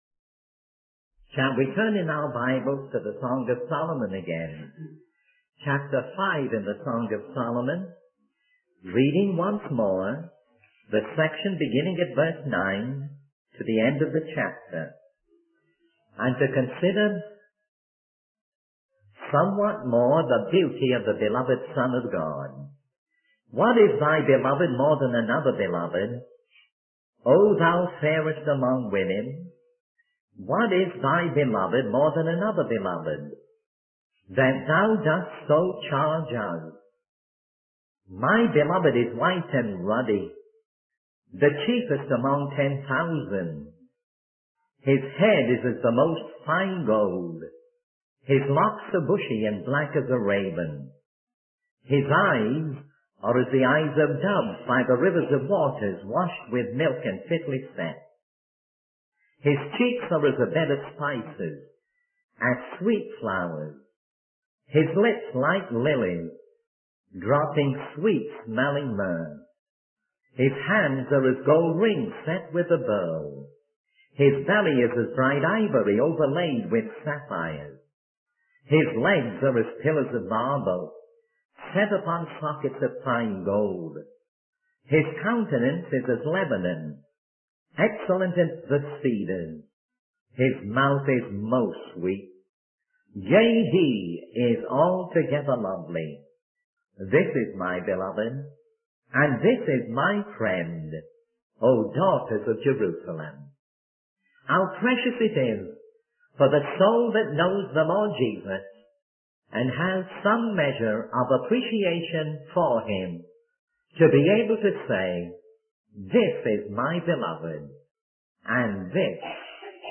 In this sermon, the speaker begins by reminiscing about fables from their school days that had moral lessons. They then share a specific fable about a girl with a wicked stepmother who encounters a fairy disguised as an old woman at a well.